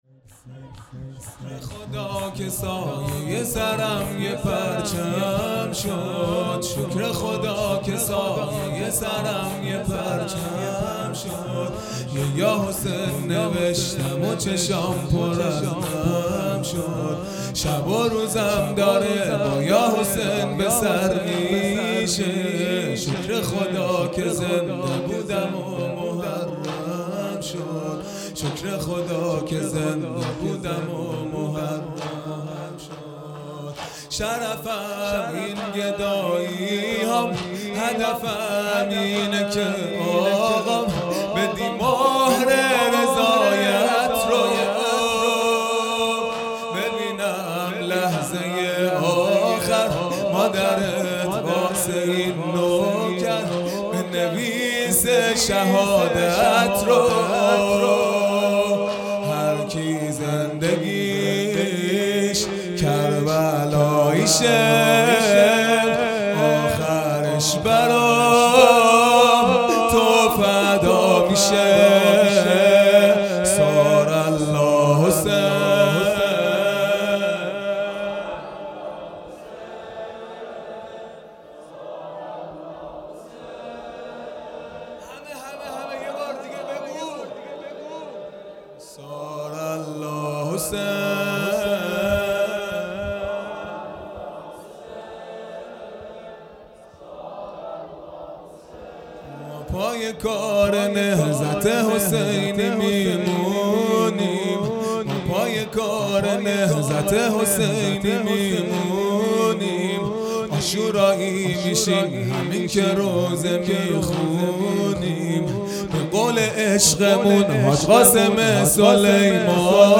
0 0 شور چهارم
دهه اول محرم الحرام ۱۴۴٢ | شام غریبان